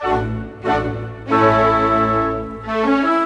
Rock-Pop 06 Orchestra 01.wav